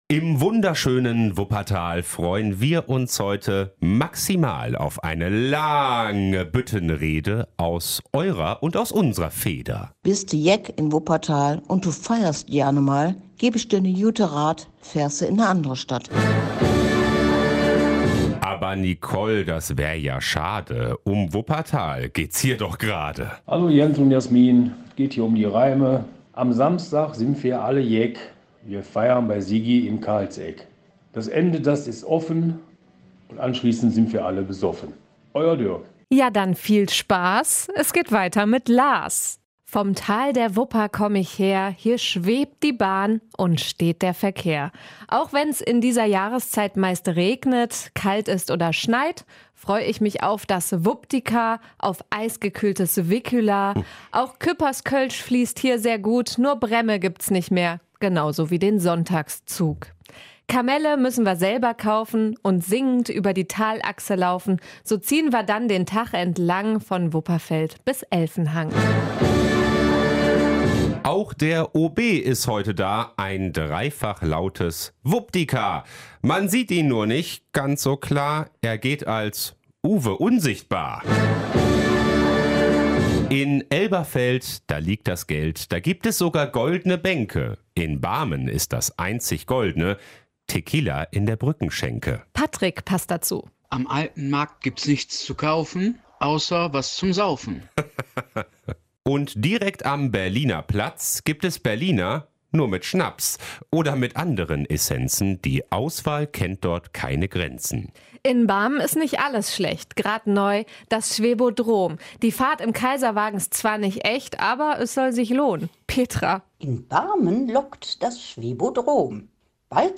Büttenrede